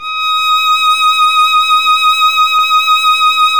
Index of /90_sSampleCDs/Roland - String Master Series/STR_Violin 2&3vb/STR_Vln2 % marc
STR  VL D#7.wav